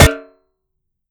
Hit_Metal 02.wav